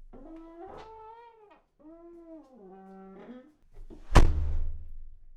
Türe
Eine Tür die anfangs behagsam, jedoch gegen Ende doch mit einiger Energie geschlossen wird.